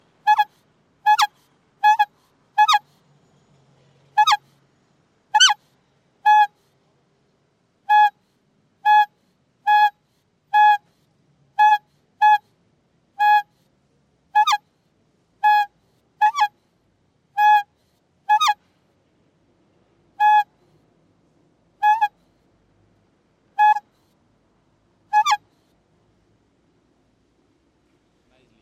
Tag: TROMP 现场录音 城市 bocina